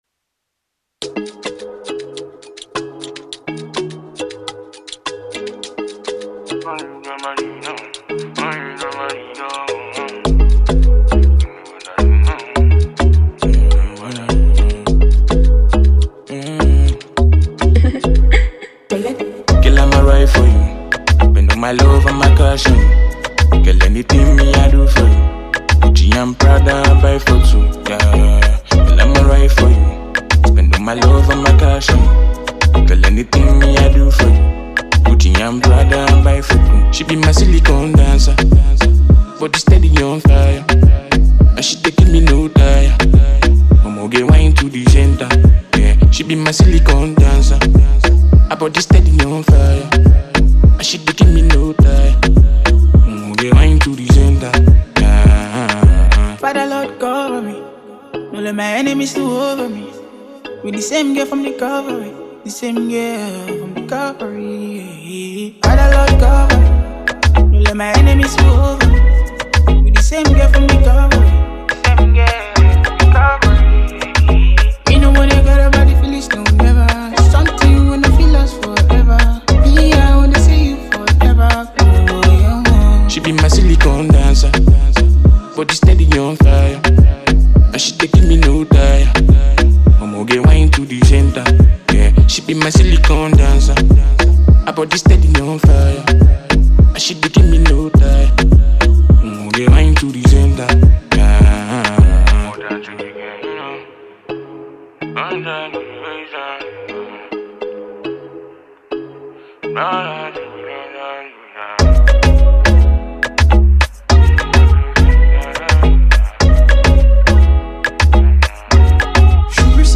Afro singer